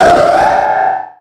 Cri_0359_Méga_XY.ogg